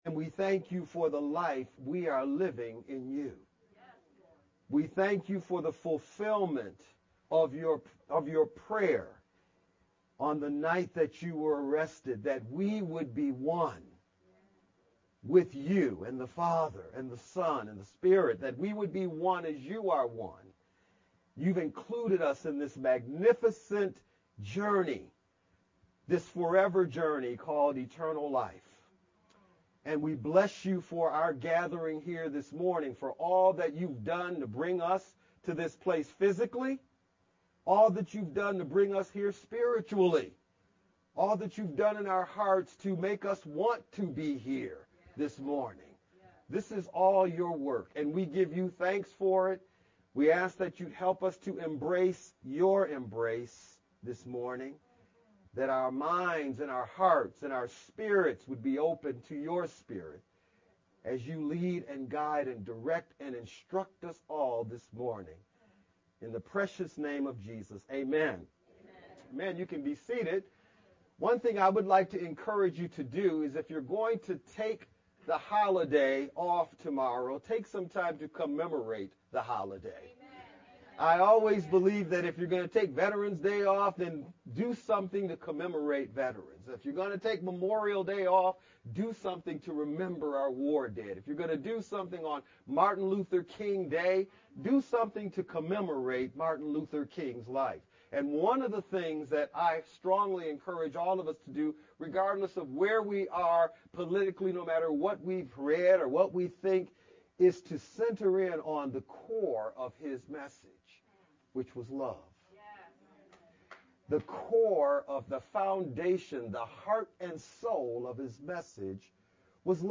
Jan-15th-VBCC-Sermon-audio-only-Mp3-CD.mp3